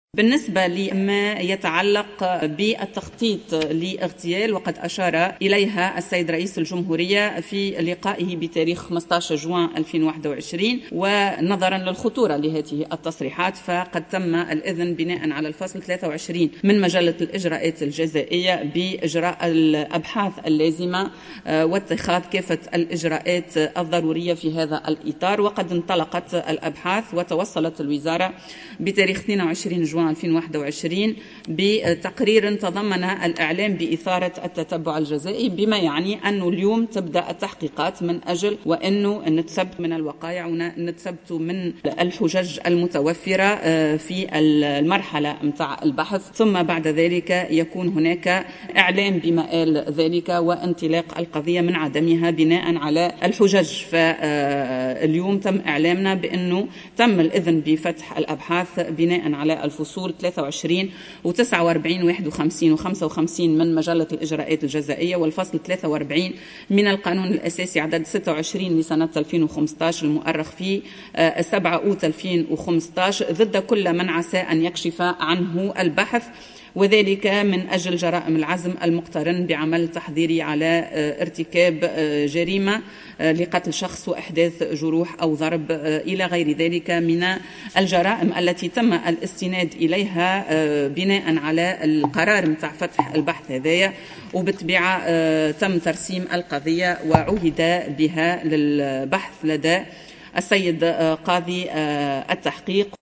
وبيّنت بن سليمان، خلال جلسة عامة بالبرلمان خُصصت لتوجيه أسئلة شفاهية، أنه نظرا لخطورة هذه التصريحات، تمّ الإذن بإجراء الأبحاث اللازمة واتخاذ الإجراءات الضرورية، وسيقع في مرحلة قادمة، الإعلان عن مآل القضية بناء على الحُجج.